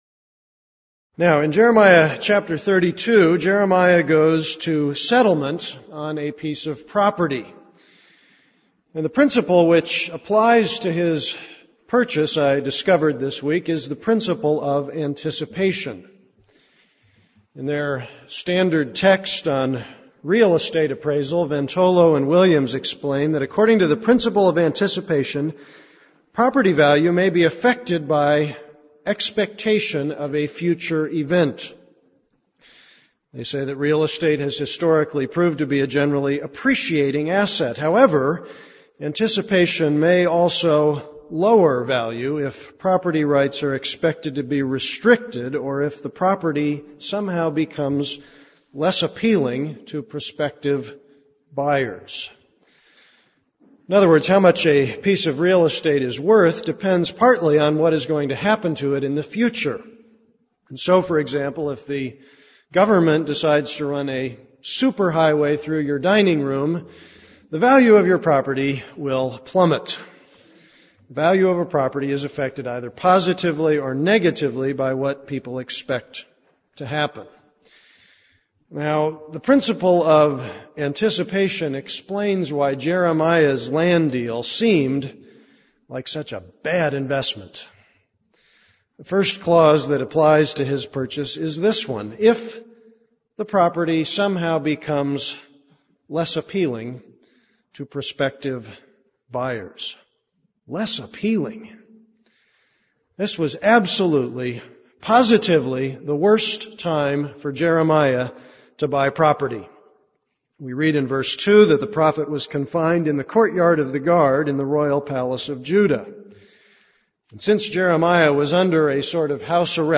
This is a sermon on Jeremiah 32:1-25.